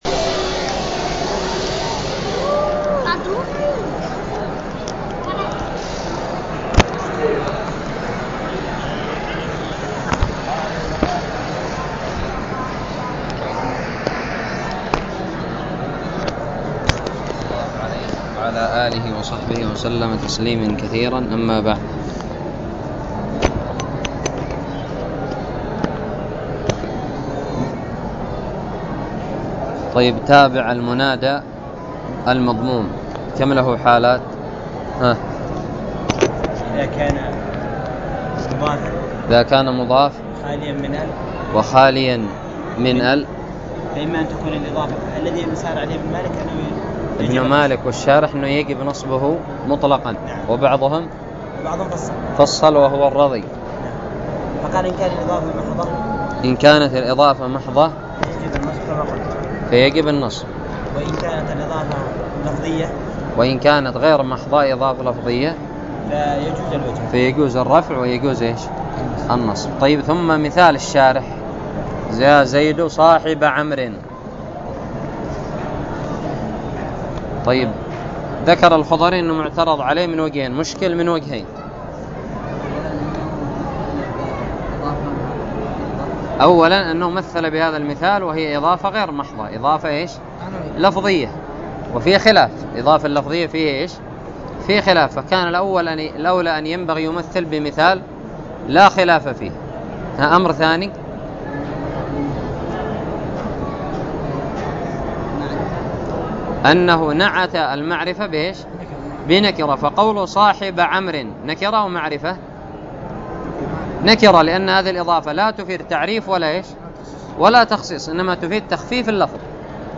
ألقيت بدار الحديث بدماج